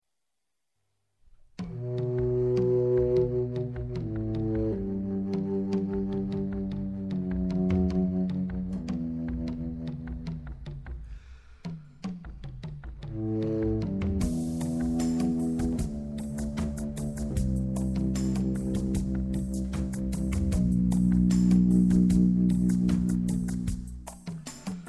The Contra Bass Flute is pitched two octaves lower than the concert flute.
Ethnic Improvisation
ethnic.mp3